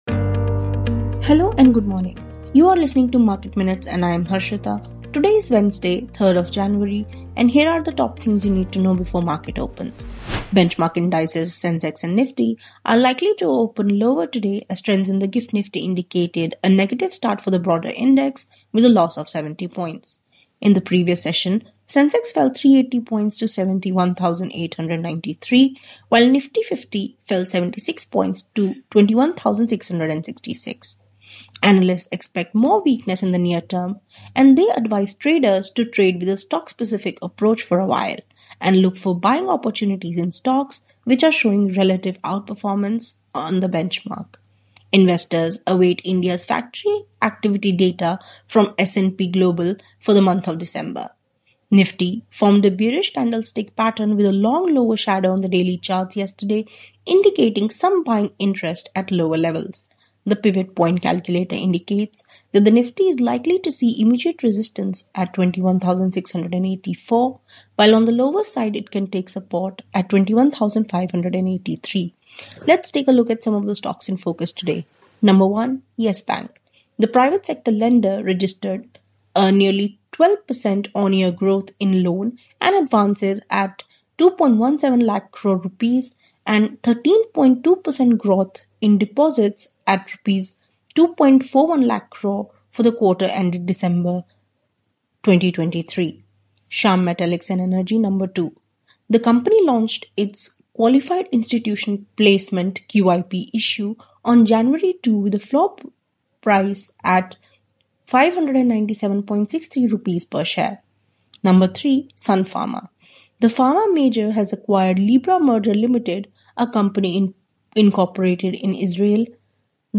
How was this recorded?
Market Minutes is a morning podcast that puts the spotlight on hot stocks, key data points, and developing trends.